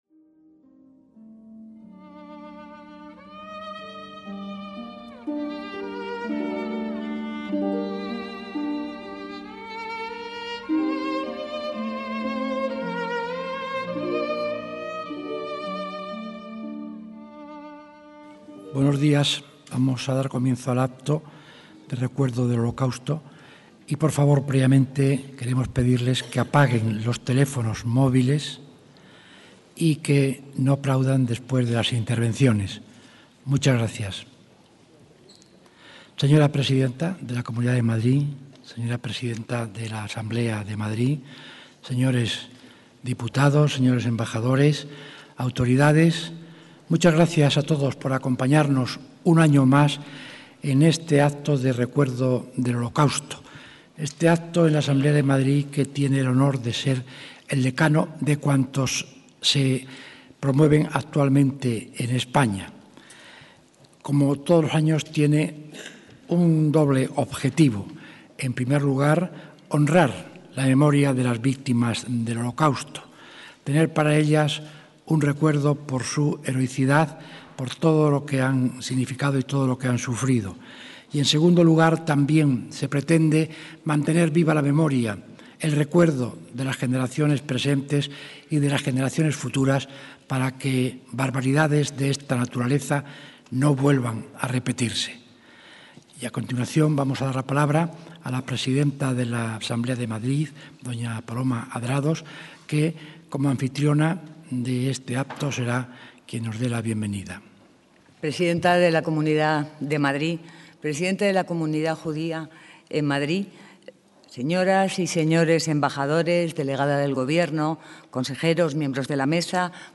ACTOS EN DIRECTO - El 29 de enero de 2016 tuvo lugar el Acto de Recuerdo del Holocausto que, desde hace ya 16 años, organiza anualmente la Asamblea de Madrid.